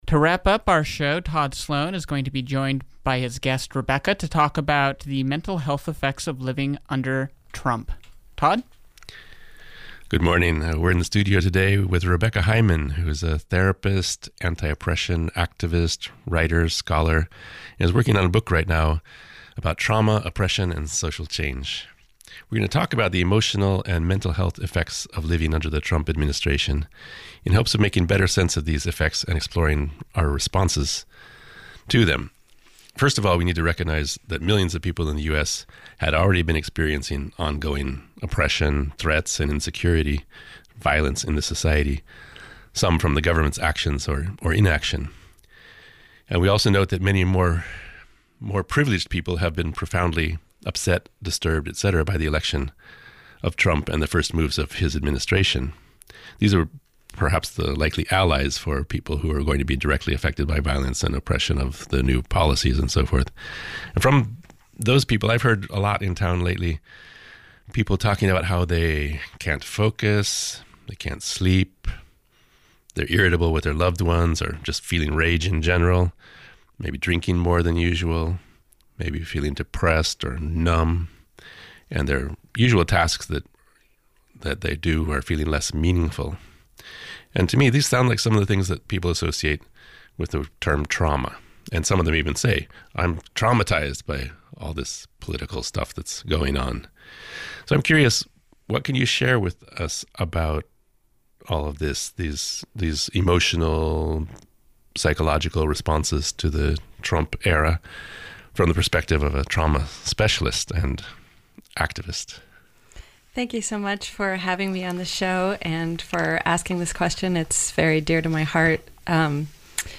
a therapist and trauma scholar